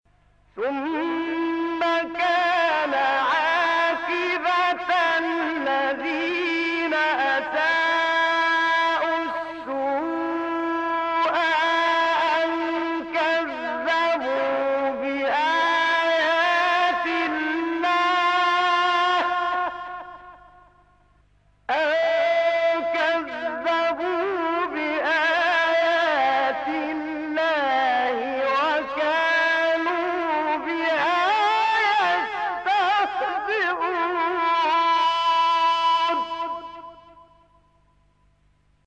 صوت | تلاوت آیه 10«سوره روم» با صوت قاریان شهیر
تلاوت آیه 10 سوره روم با صوت محمد عبدالعزیز حصان